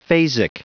Prononciation du mot phasic en anglais (fichier audio)
Prononciation du mot : phasic